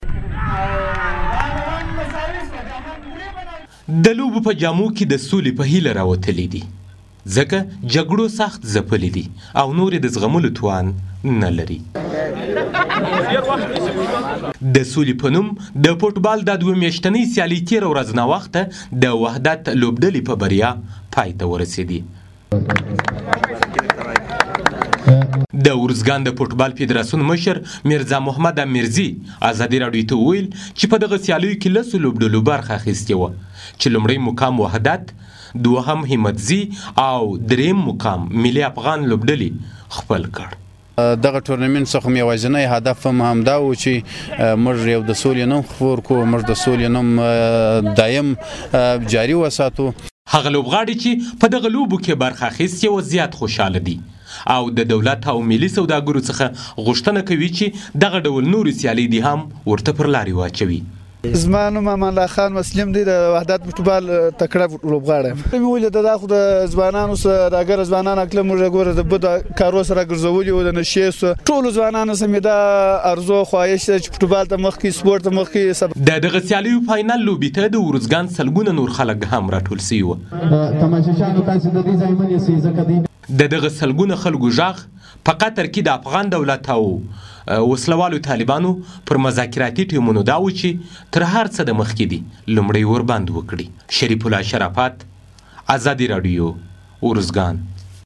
ارزګان راپور